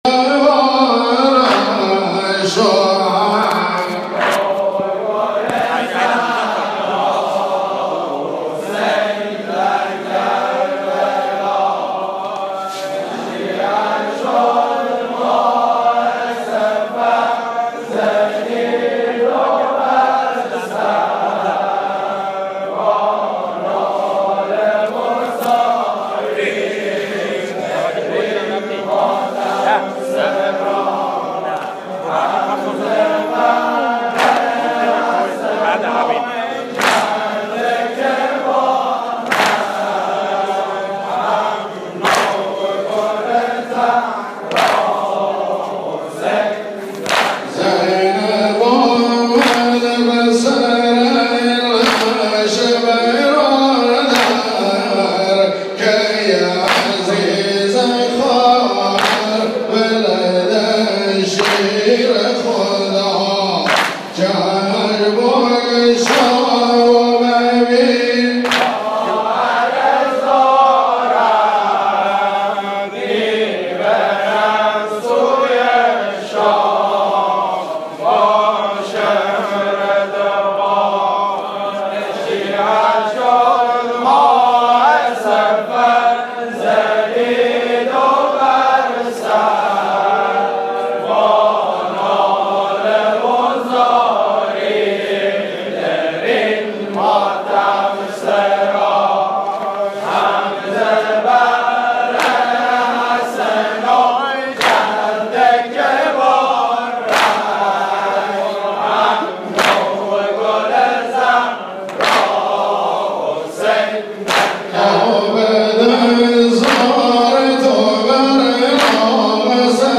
آوای لیان - دانلود نوحه و عزاداری سنتی بوشهر
مراسم سینه زنی اربعین ۱۳۹۴ | مسجد شیخ سعدون